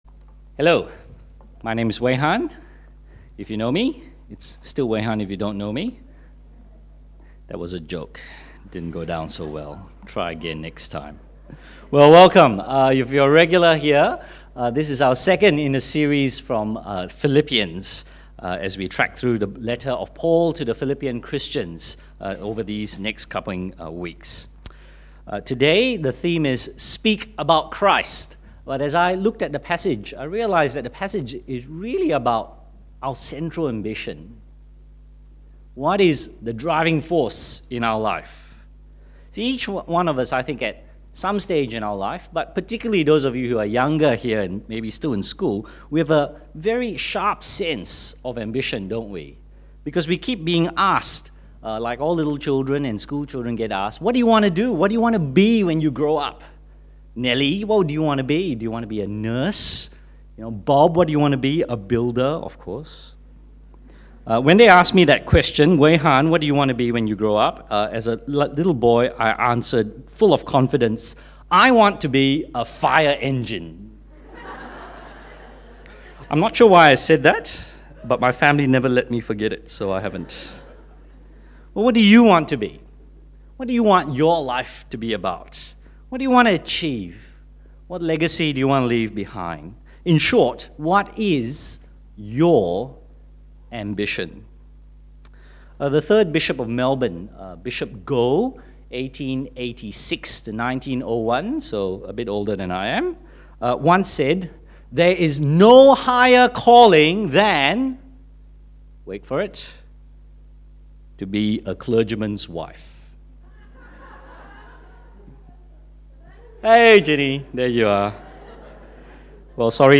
Sermons | St Alfred's Anglican Church
Bible Passage